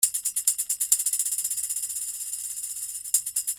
135 BPM Tambourine (6 variations)
6 real tambourine loops playing at 135 bpm.
The tambourine in this speed would be great in tropical house music
The tambourine was treated with hit point, that way combination Between electronic music and acoustic music gives life to the melody.